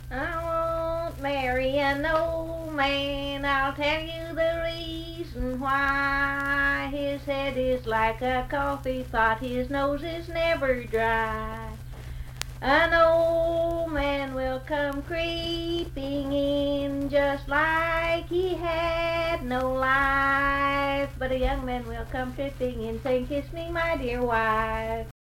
Unaccompanied vocal music
Dance, Game, and Party Songs, Marriage and Marital Relations
Voice (sung)
Richwood (W. Va.), Nicholas County (W. Va.)